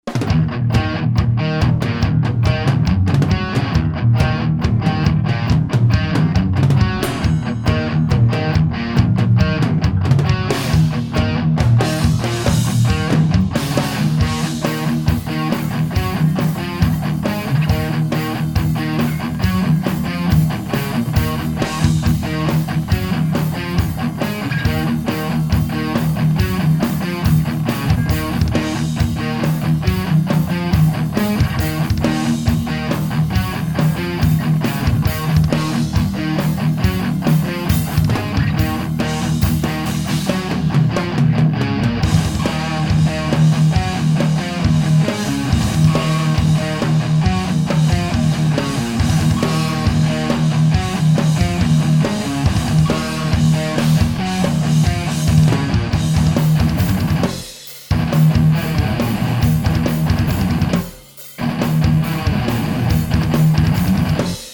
Pre vsetkych priaznivcov internetoveho mudrovania a pocuvania a porovnavania ukazok som pripravil 6 vzoriek kvazy toho isteho - 2 beglajtove gitary + basa + bicie. Rozdiel je iba v gitarach, boli pouzite viacere gitarove aparaty alebo modeling.
ukazka 5 znie najhrosie, podla mna simualcia nejaka :D